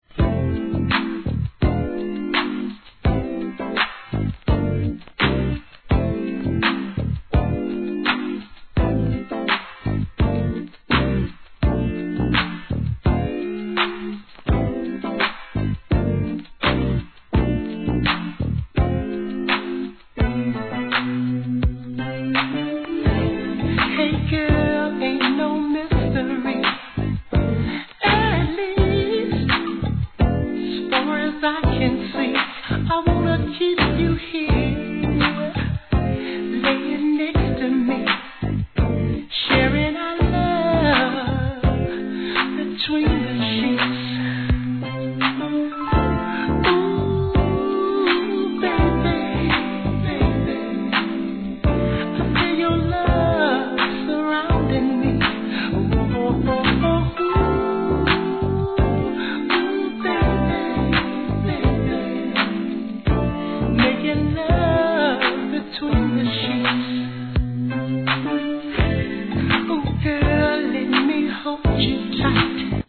SOUL/FUNK/etc...
身を任せて癒されたいって方はこれで決まり!!